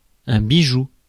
Ääntäminen
IPA: [bi.ʒu]